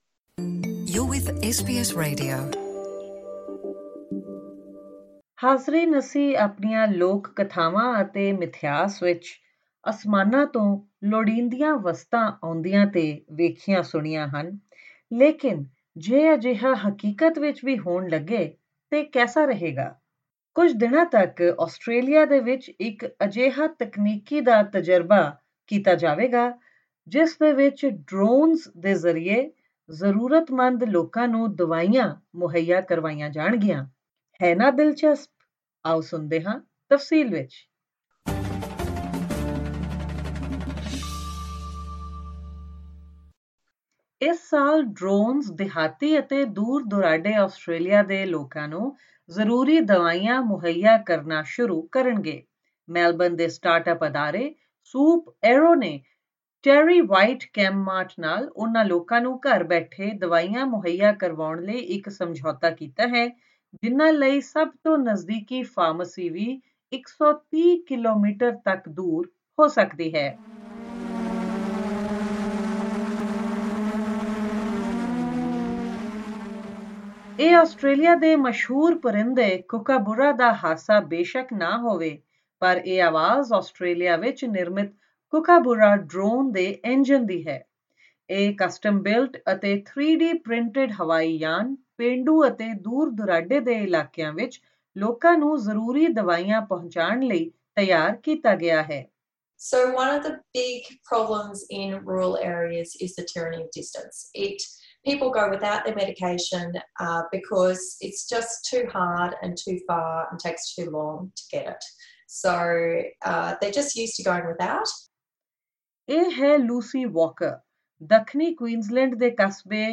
It might not be the iconic laugh of a kookaburra. But it is the engine of the Australian-made kookaburra drone.